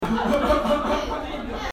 Play, download and share Risadinha W original sound button!!!!
risadinha_3.mp3